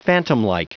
Prononciation du mot phantomlike en anglais (fichier audio)